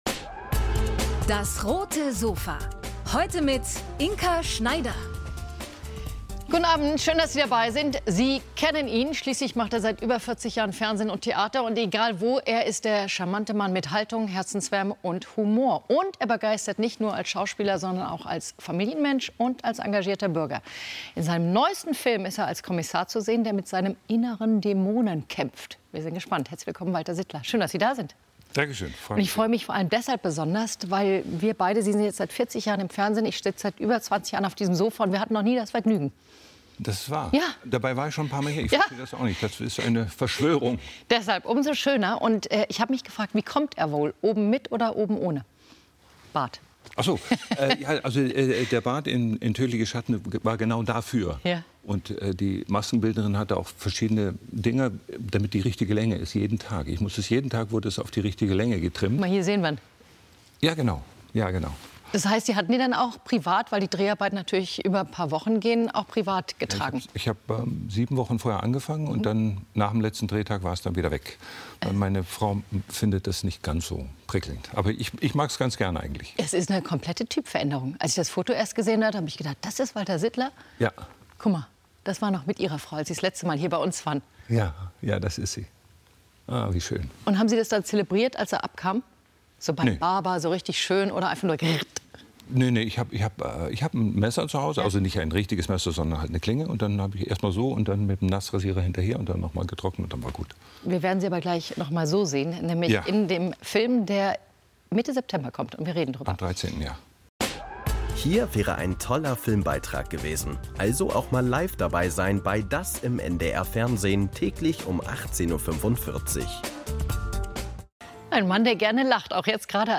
Schauspieler Walter Sittler auf dem Roten Sofa ~ DAS! - täglich ein Interview Podcast